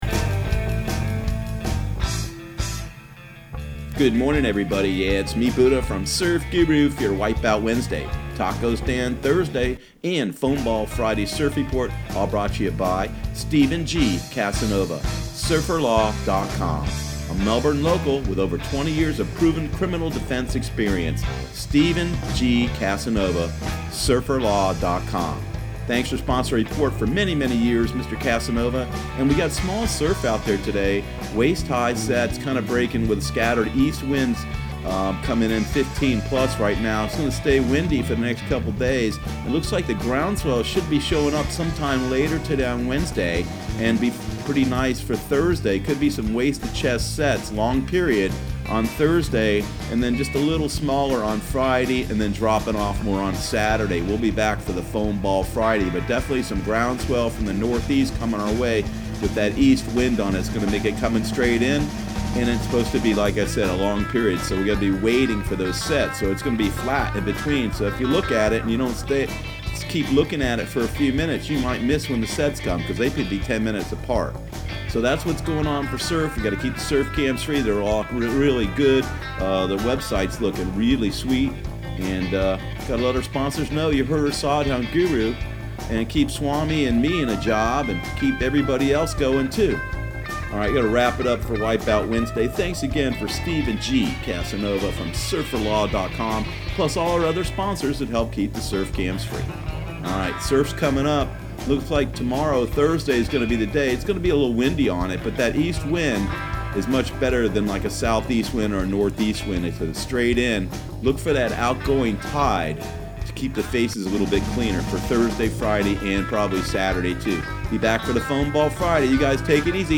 Surf Guru Surf Report and Forecast 05/08/2019 Audio surf report and surf forecast on May 08 for Central Florida and the Southeast.